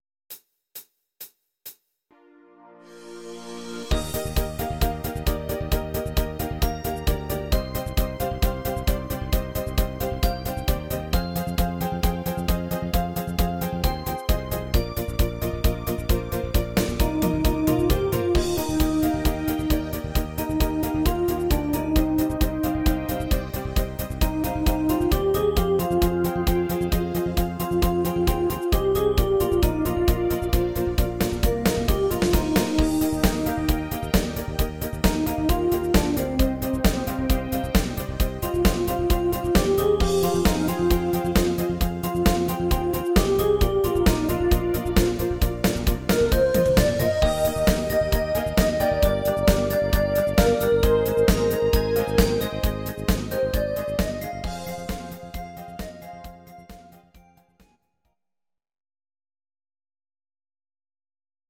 These are MP3 versions of our MIDI file catalogue.
Please note: no vocals and no karaoke included.
Your-Mix: Instrumental (2065)